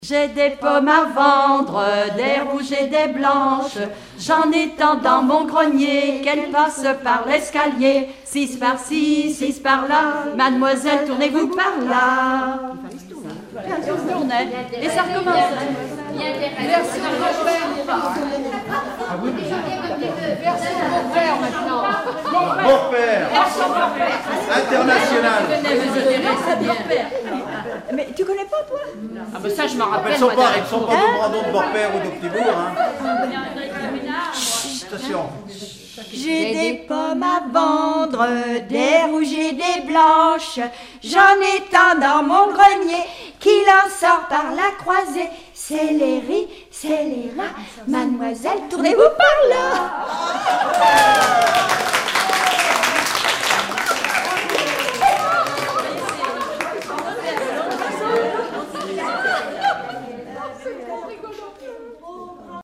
enfantine : jeu de balle
Regroupement de chanteurs du canton
Pièce musicale inédite